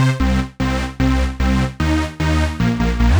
Index of /musicradar/future-rave-samples/150bpm